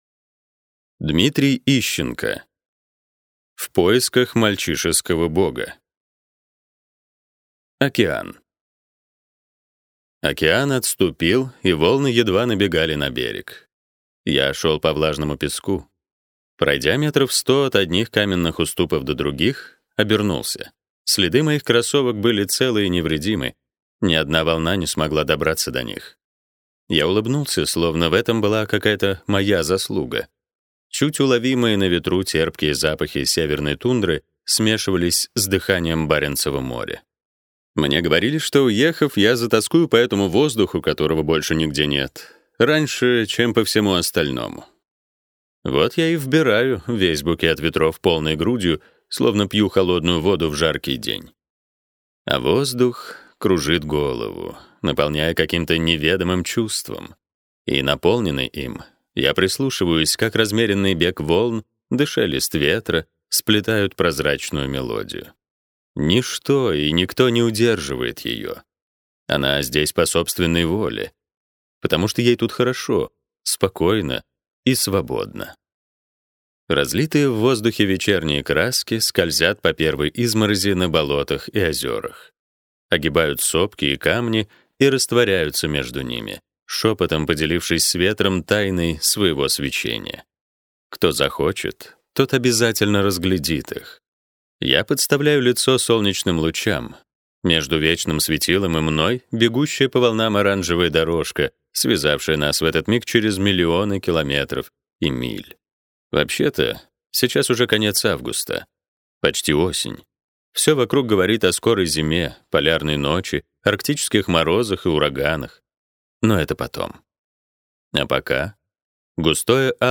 Аудиокнига В поисках мальчишеского бога | Библиотека аудиокниг